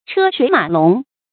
车水马龙 chē shuǐ mǎ lóng
车水马龙发音
成语正音 水，不能读作“suǐ”。